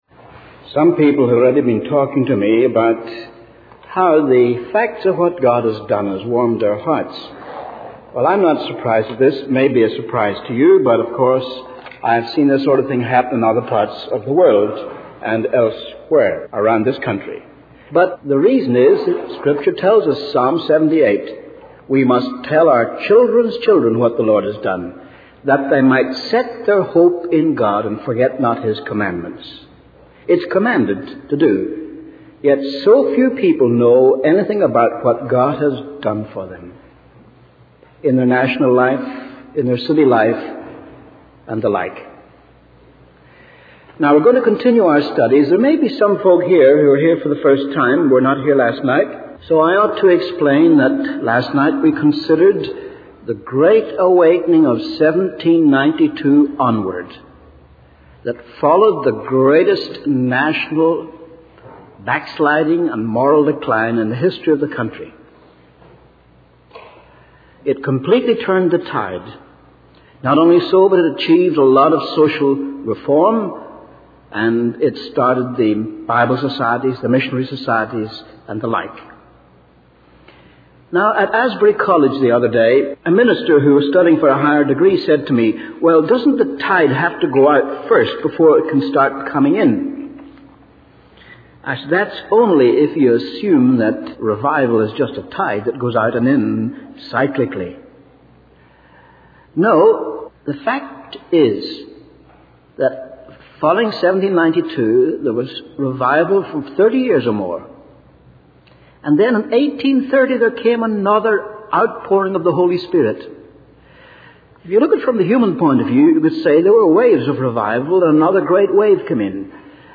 In this sermon, the speaker emphasizes the importance of sharing the stories of what God has done with future generations. He shares an anecdote about an aged Presbyterian minister who was deeply convicted of his shortcomings and sobbed while holding onto a tree.